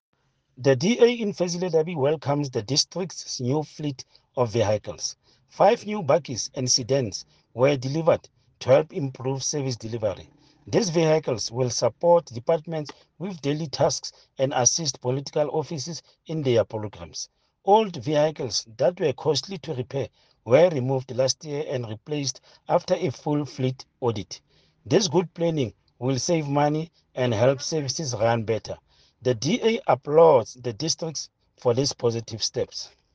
Sesotho soundbites by Cllr Stone Makhema and Afrikaans soundbite by Cllr Teboho Thulo.